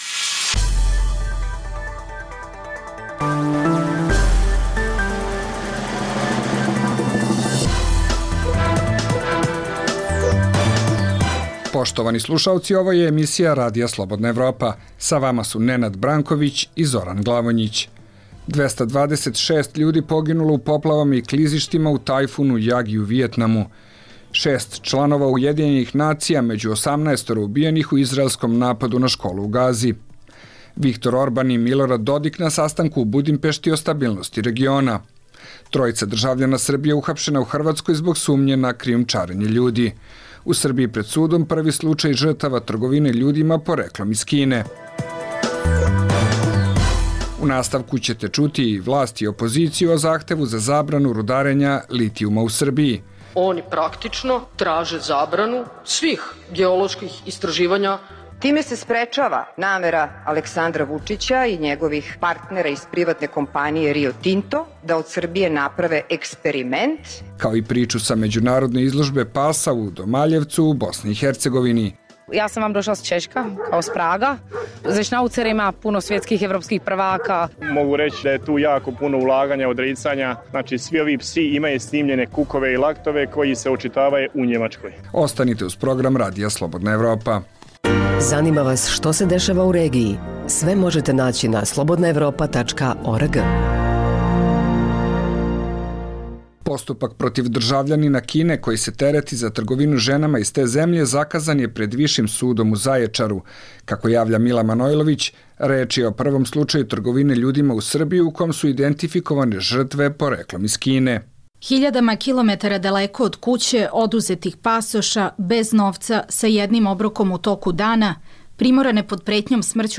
Poslušajte Aktuelno, regionalni radijski program
Dnevna informativna emisija Radija Slobodna Evropa o događajima u regionu i u svijetu. Vijesti, teme, analize i komentari.